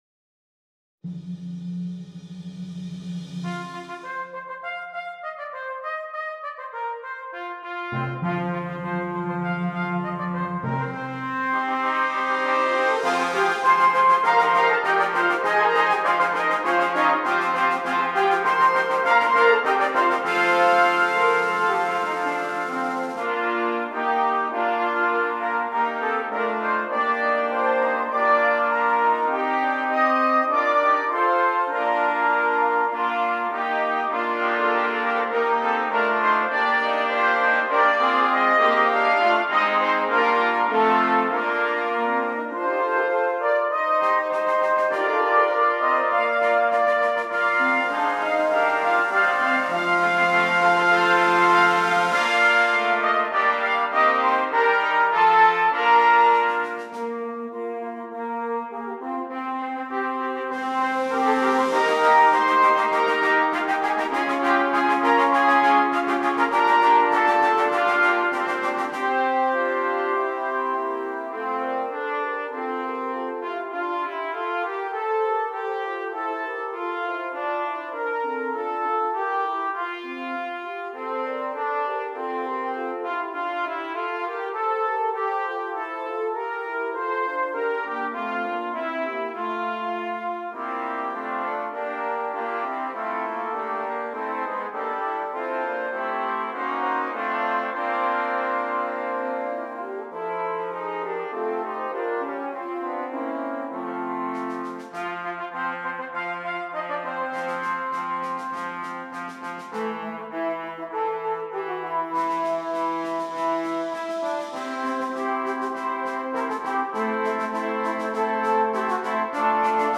9 Trumpets
This is an exciting piece